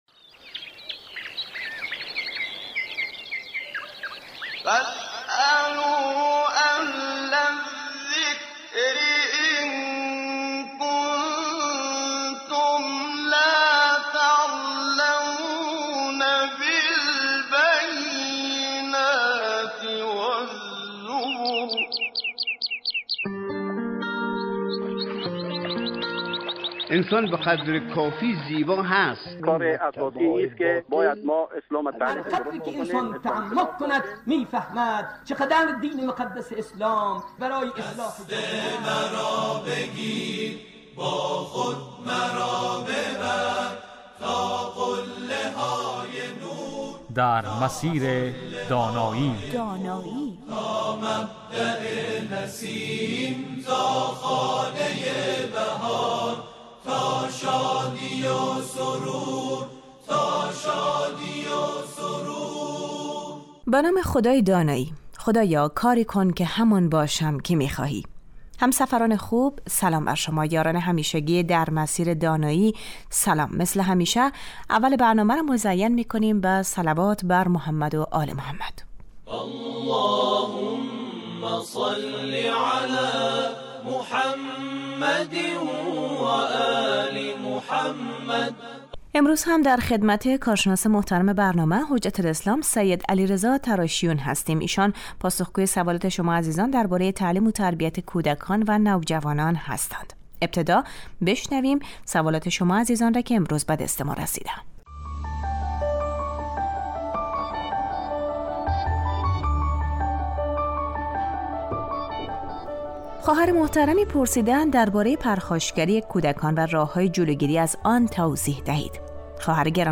در این برنامه در سه روز اول هفته در مورد تعلیم تربیت کودک و نوجوان از دیدگاه اسلام و در سه روز پایانی هفته در مورد مسایل اعتقادی و معارف اسلامی بحث و گفتگو می شود .